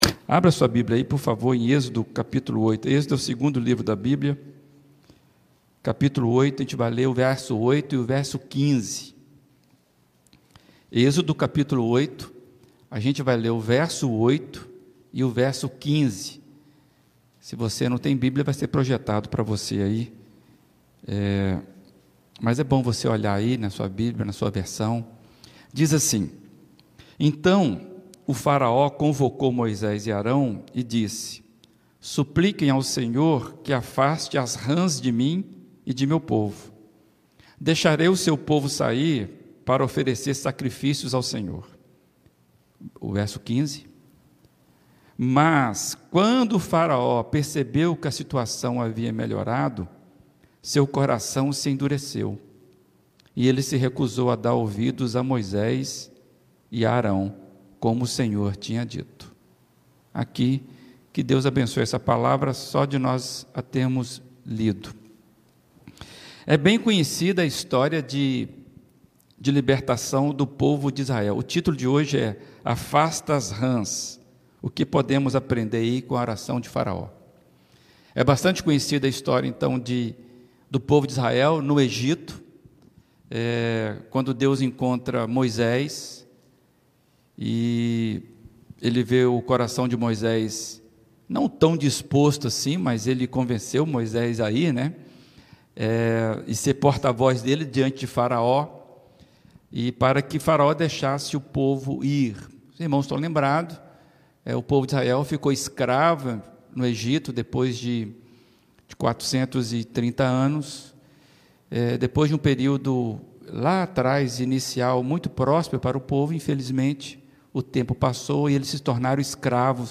Mensagem